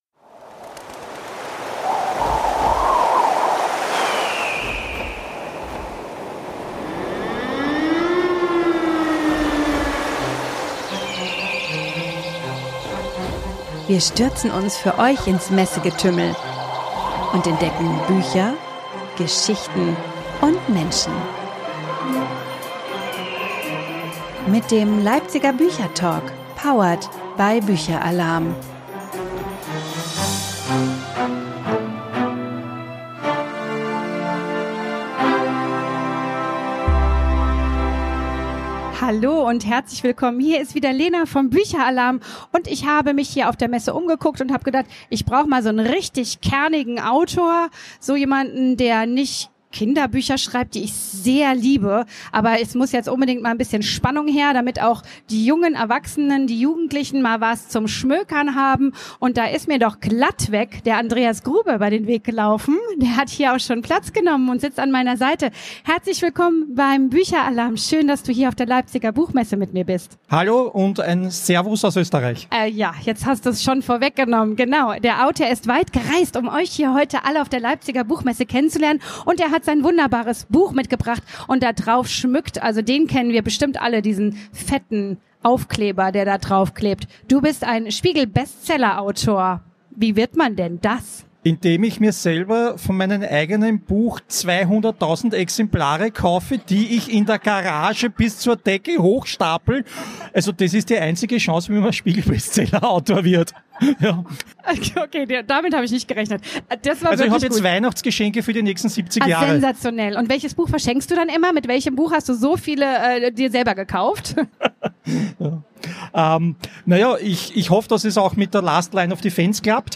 Während Grubers Geschichten an Spannung kaum zu überbieten sind, ist sein Humor im Live-Podcast ein absolutes Highlight.